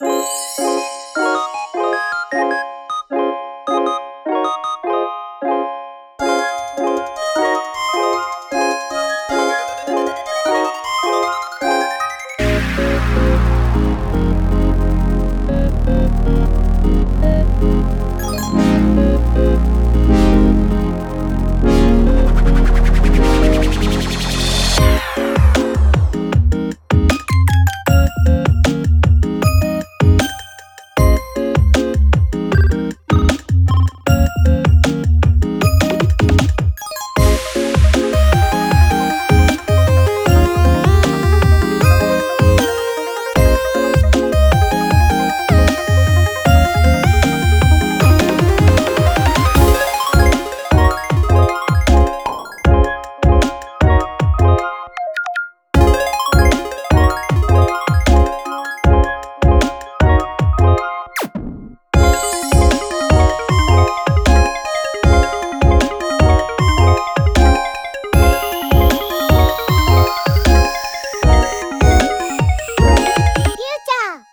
◆ジャンル：FutureBass/フューチャーベース
-10LUFSくらいでマスタリングしております。